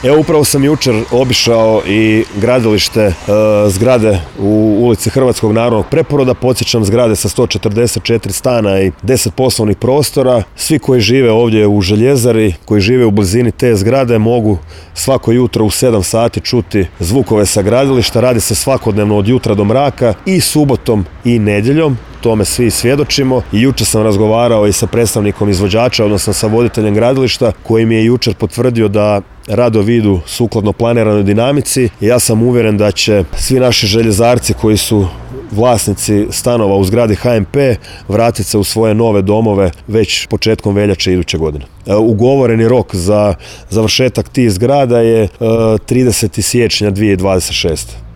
Na trenutnu dinamiku radova izgradnje ovog stambenog bloka u gradskoj četvrti Caprag, osvrnuo se državni tajnik Ministarstva prostornoga uređenja, graditeljstva i državne imovine Domagoj Orlić